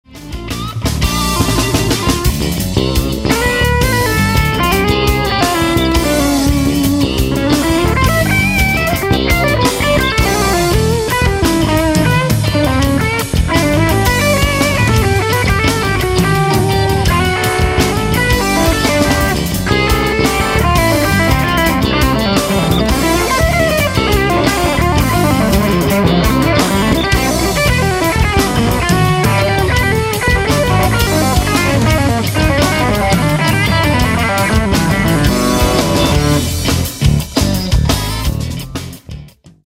... the NON HRM is "wider" and a tad smoother to me.
I like the slightly looser , silky feel of the NON HRM clip.
I also like the slippier opening phrase on the second clip.